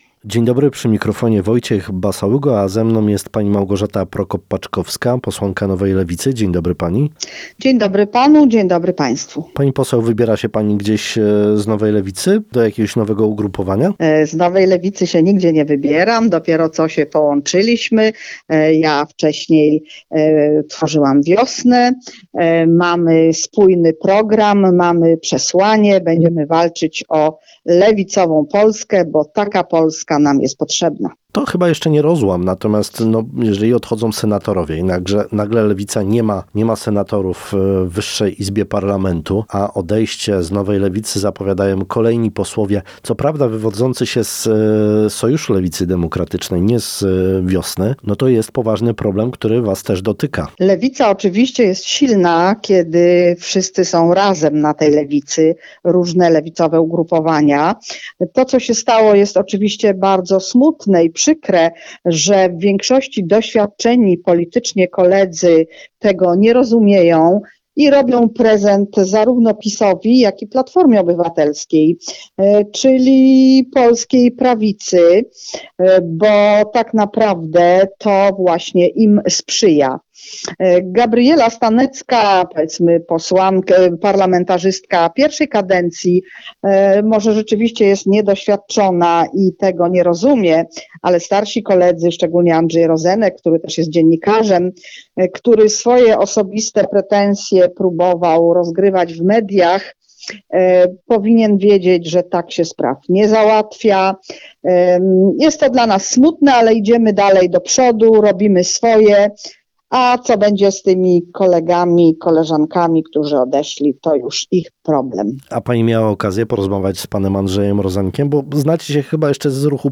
TVN teraz, kto następny? Dzisiejszym gościem w Rozmowie Dnia jest Małgorzata Prokop-Paczkowska